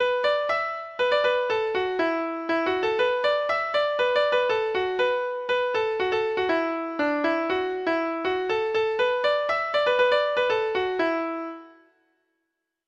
Folk Songs from 'Digital Tradition' Letter I I Will Set My Ship In Order
Free Sheet music for Treble Clef Instrument